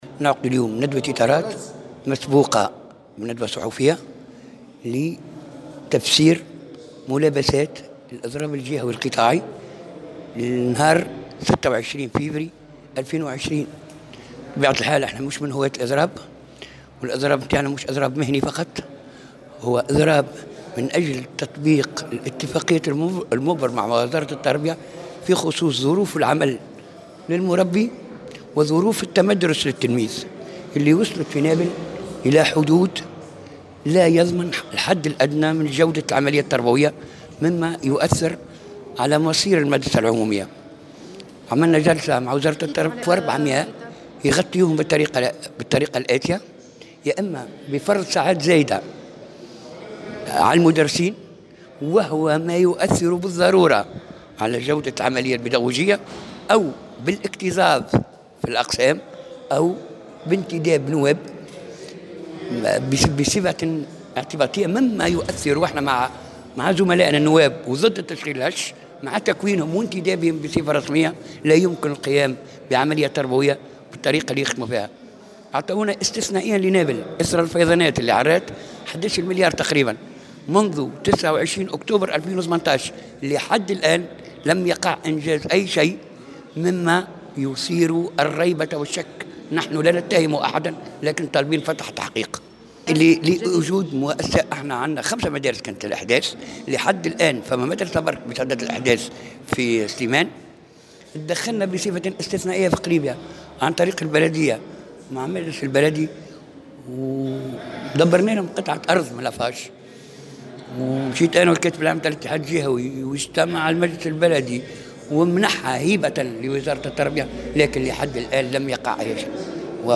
عقد الفرع الجامعي للتعليم الاساسي بمقر الاتحاد الجهوي للشغل بنابل، اليوم الأربعاء، ندوة صحفية للإعلان عن تنفيذ إضراب جهوي في التعليم الاساسي يوم 26 فيفري 2020.